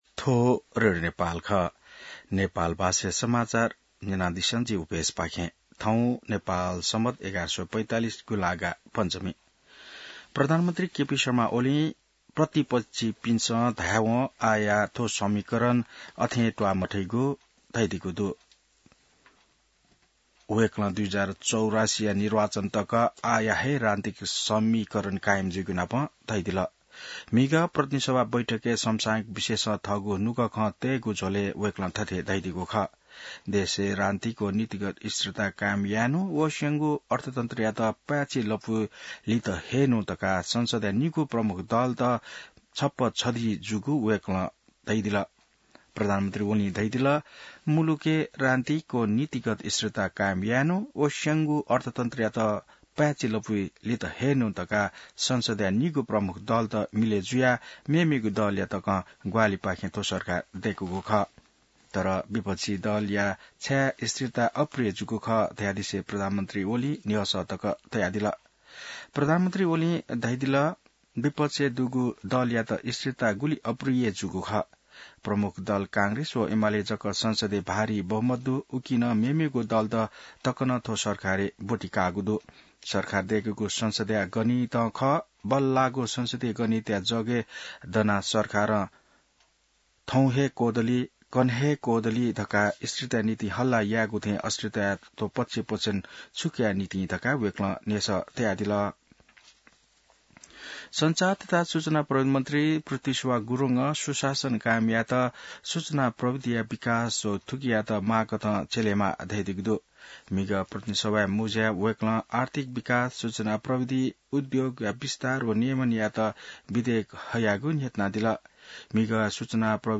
नेपाल भाषामा समाचार : २९ साउन , २०८२